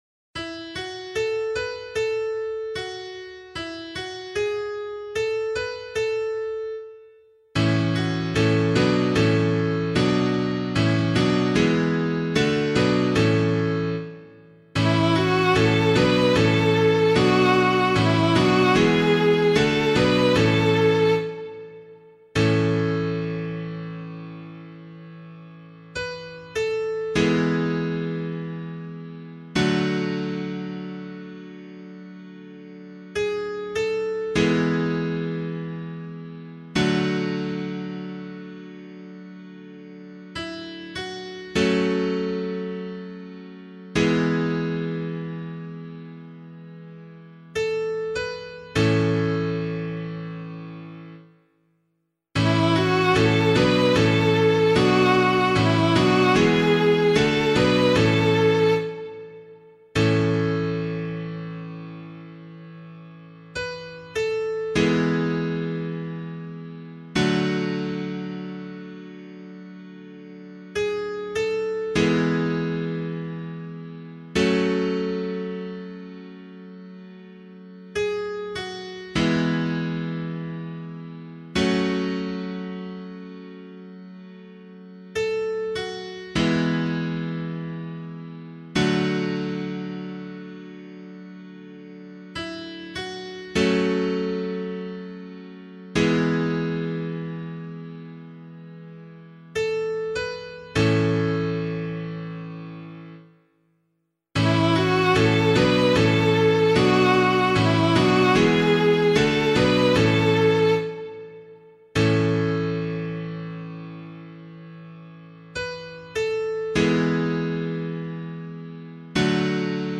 001 Advent 1 Psalm A [LiturgyShare 8 - Oz] - piano.mp3